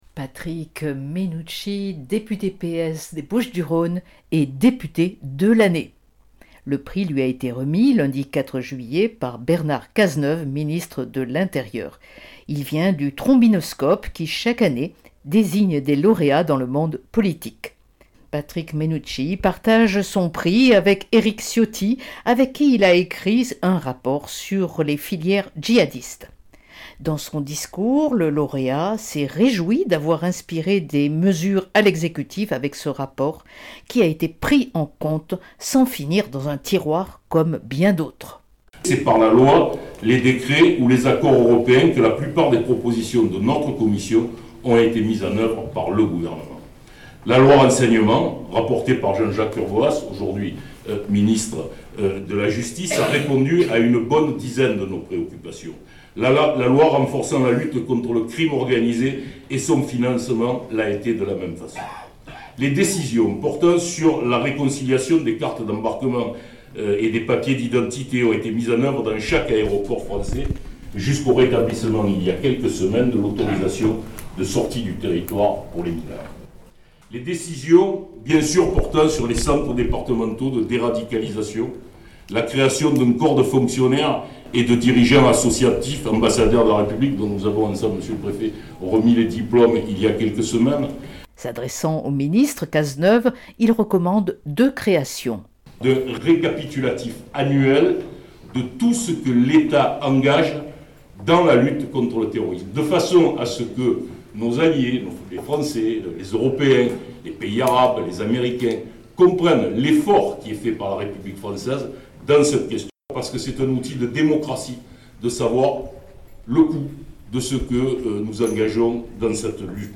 Le prix du “député de l’Année” a été remis au socialiste Patrick Mennucci pour son rapport sur les filières djihadistes. Cette récompense, attribuée chaque année par un jury de journalistes réunis par Le Trombinoscope, annuaire professionnel du monde politique français, a été remise par le ministre de l’Intérieur Bernard Cazeneuve lors d’une cérémonie qui s’est déroulée à la Villa Méditerranée.
Reportage son